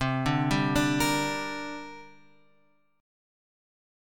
C 7th Suspended 2nd